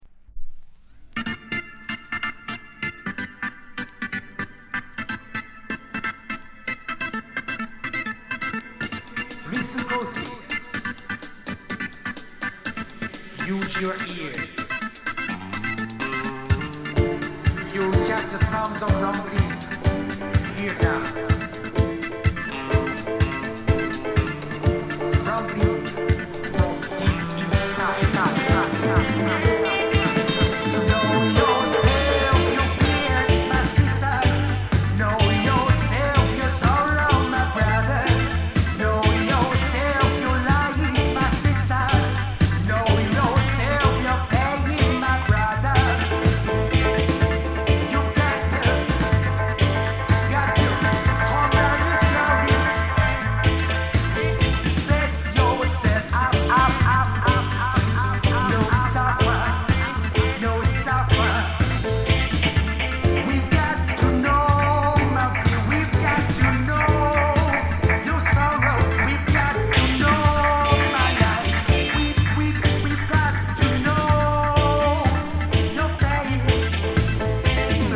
日本? 7inch/45s